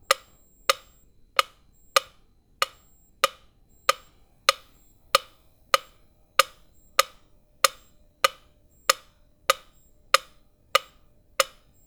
Tiempo adagio en un metrónomo
adagio
metrónomo